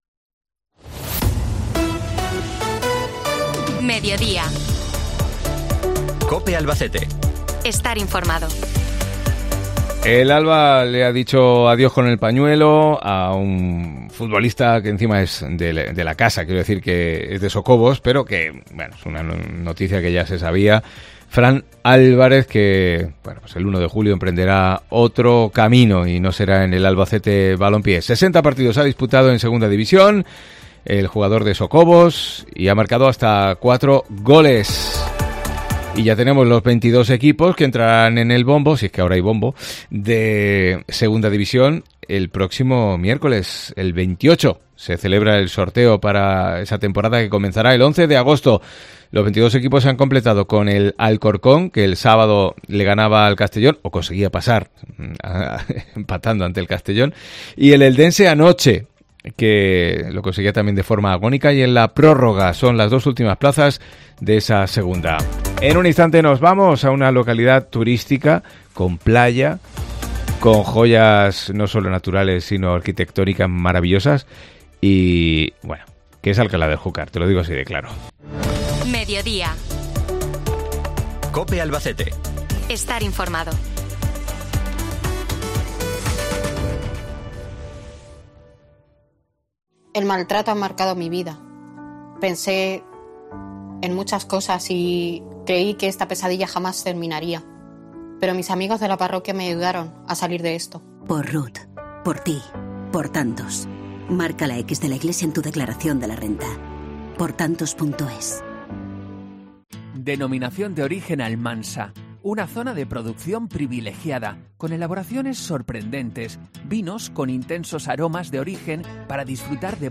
Entrevista con el nuevo alcalde de Alcalá del Júcar, Juan Pascual...